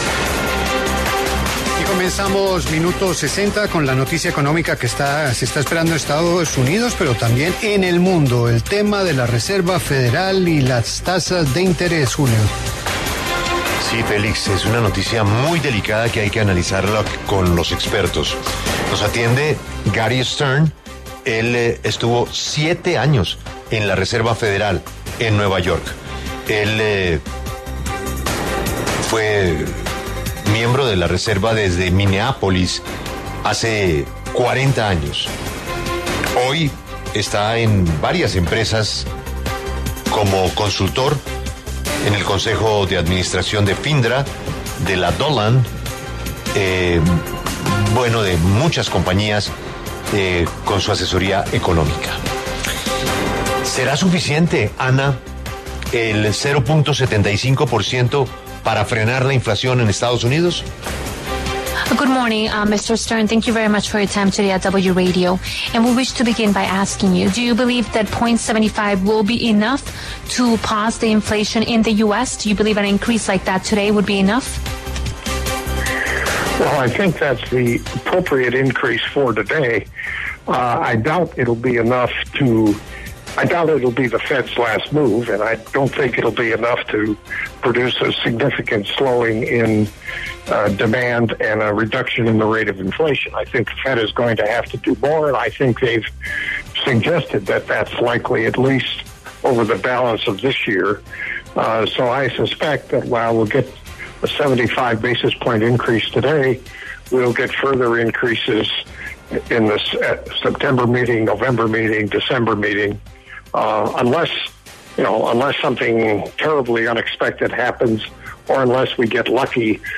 Gary H. Stern, economista estadounidense que fue presidente y director general de la Reserva Federal de Minneapolis desde 1985 hasta 2009, habló en La W sobre una posible subida de los tipos de interés en Estados Unidos.